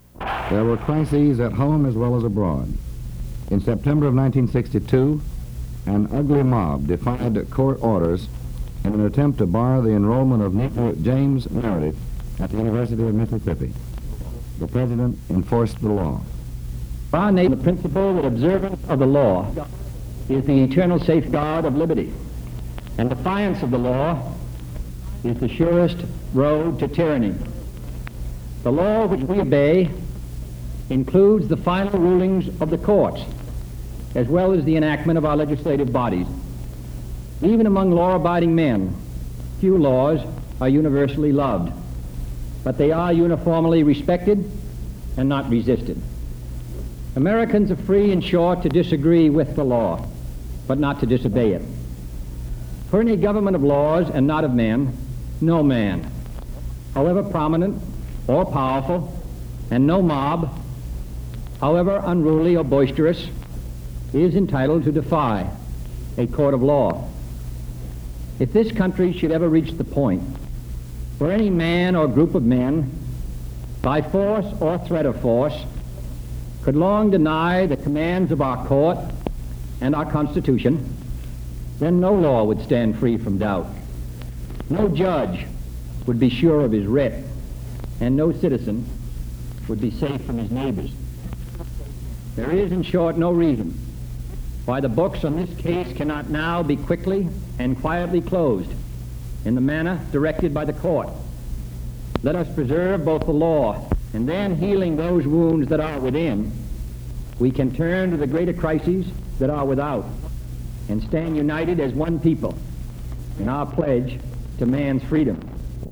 U.S. President John F. Kennedy insists on the right of James Meredith to attend the University of Mississippi. Recording preceded by an introduction from U.S. Secretary of State Dean Rusk.